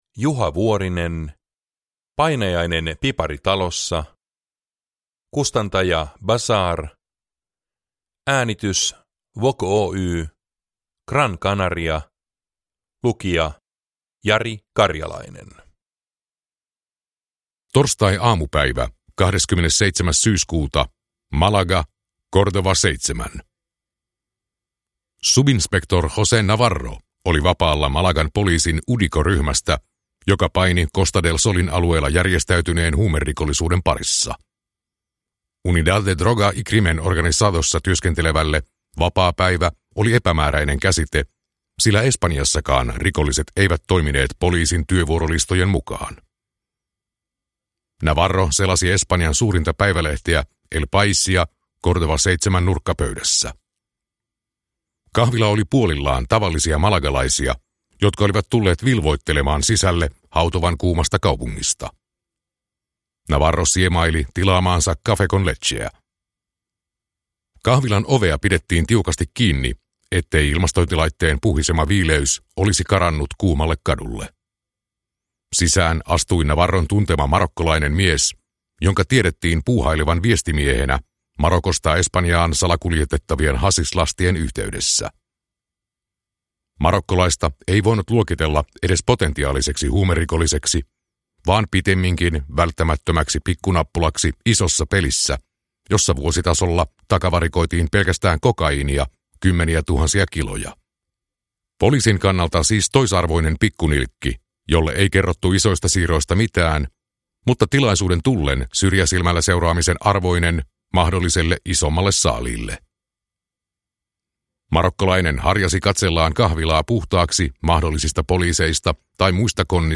Painajainen piparitalossa – Ljudbok